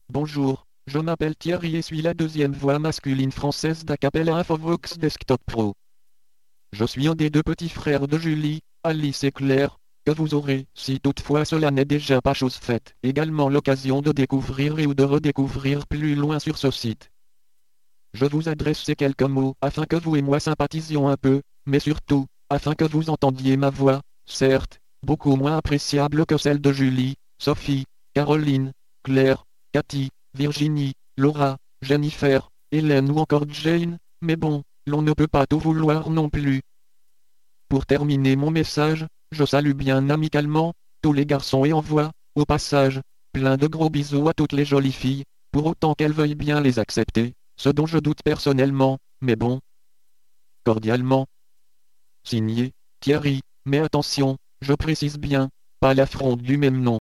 Texte de démonstration lu par Thierry, deuxième voix masculine française d'Acapela Infovox Desktop Pro
Écouter la démonstration de Thierry, deuxième voix masculine française d'Acapela Infovox Desktop Pro